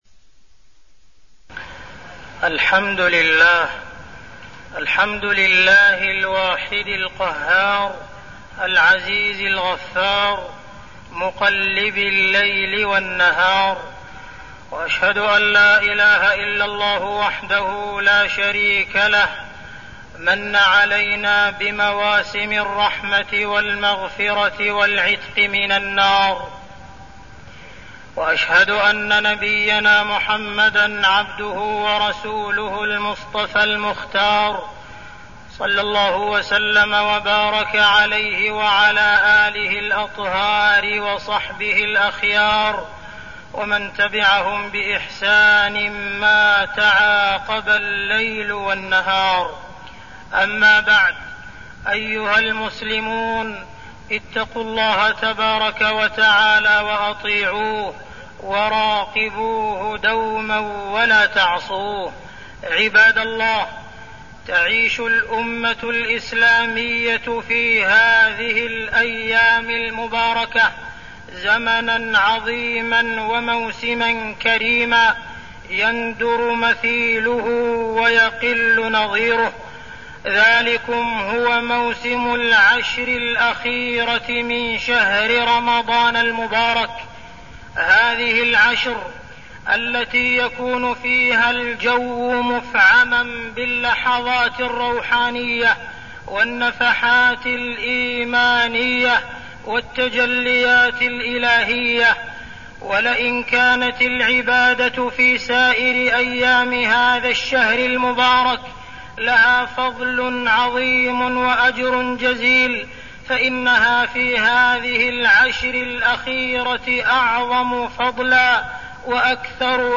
تاريخ النشر ٢٥ رمضان ١٤١٠ هـ المكان: المسجد الحرام الشيخ: معالي الشيخ أ.د. عبدالرحمن بن عبدالعزيز السديس معالي الشيخ أ.د. عبدالرحمن بن عبدالعزيز السديس العشر الأواخر من رمضان وفضلها